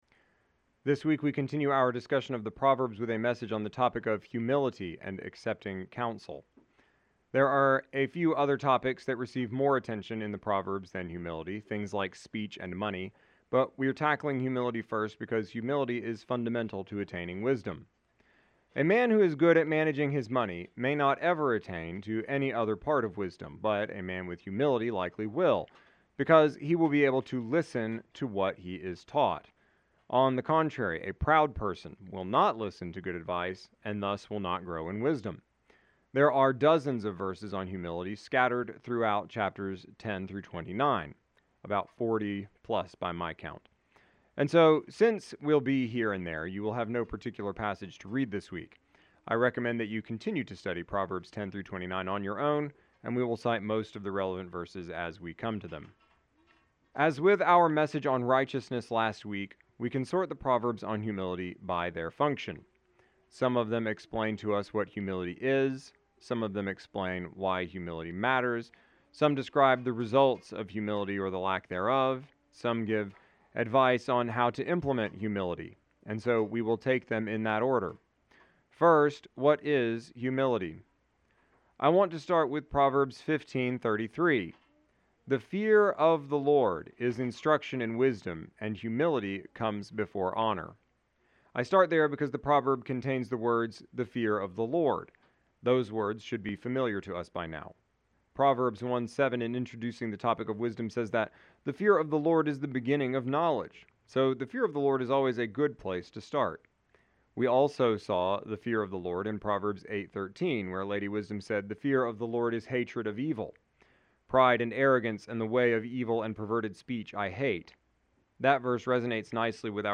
exegetical sermon series through the entire Bible.